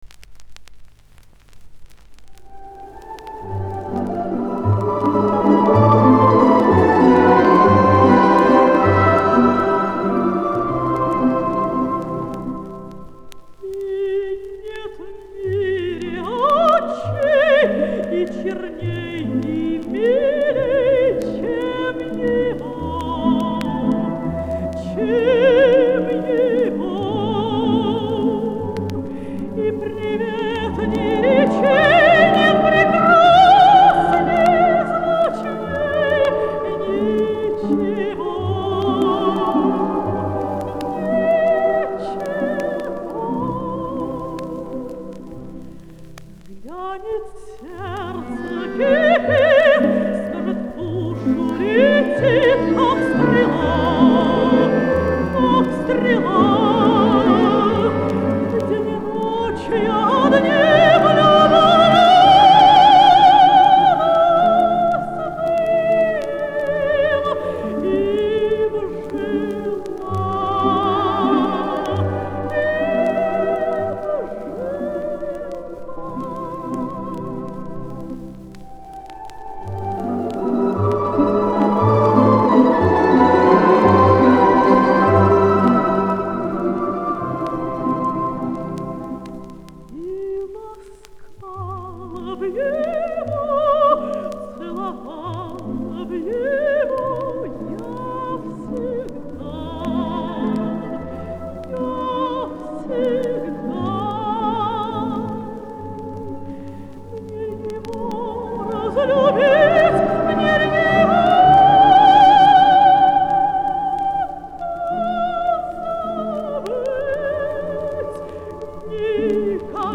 Мария Каллас, знаменитое сопрано, репетировала в Ла Скала 'Медею' Рубини.
Звуковая страница 8 - Поёт Тамара Милашкина. П.Булахов. 'И нет в мире очей'.
Фрагмент из арии Аиды.